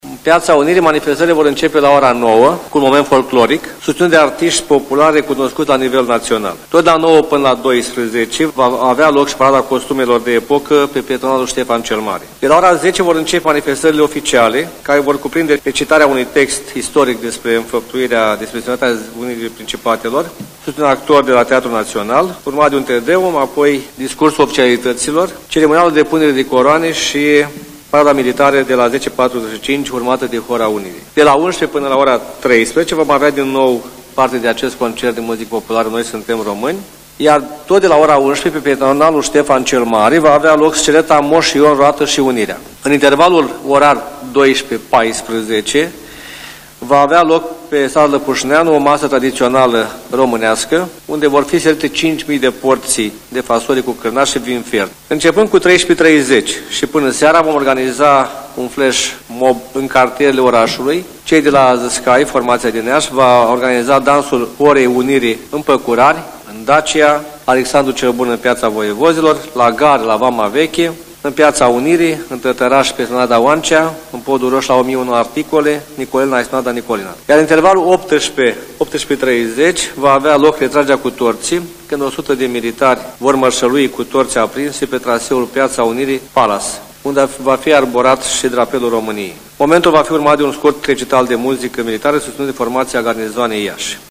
Primarul localităţii, Gheorghe Nichita, a precizat astăzi într-o conferinţă de presă că doreşte să semneze un parteneriat cu celelalte două municipii care să vizeze colaborarea în domeniile educaţional, cultural şi al administraţiei publice.